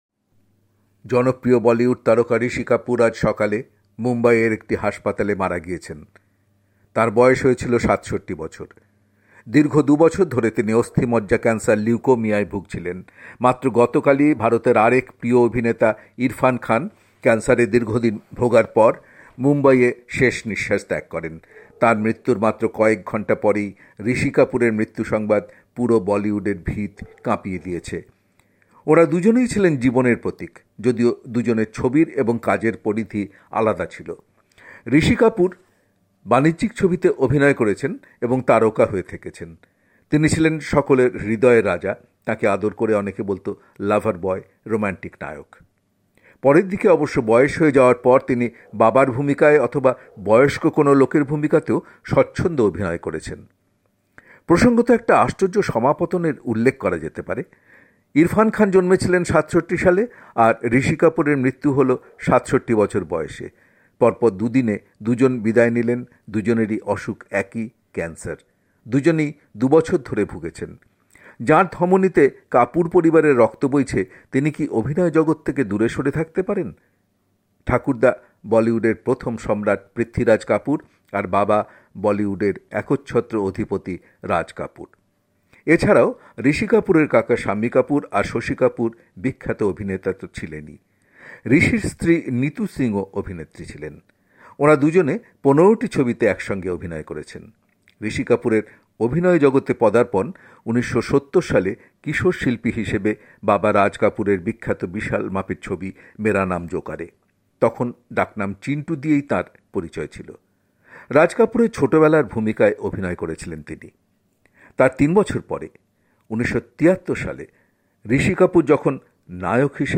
কলকাতা থেকে
রিপোর্ট।